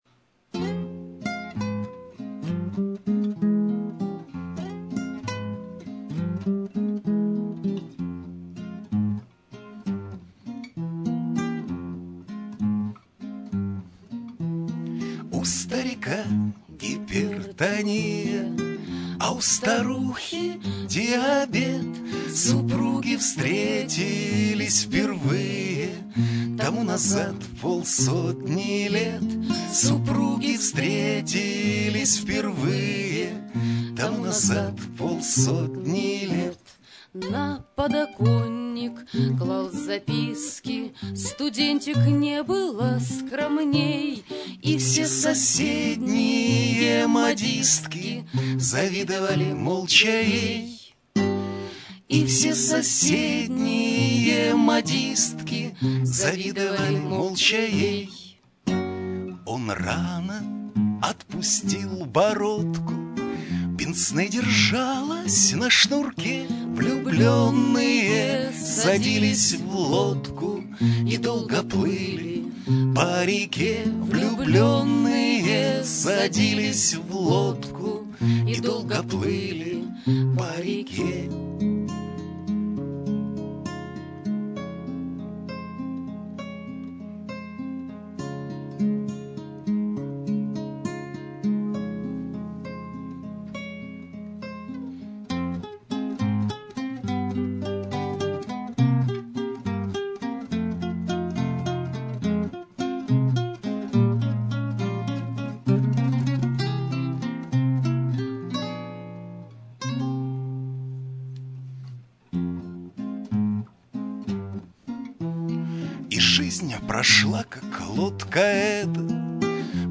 Музыкальный антракт)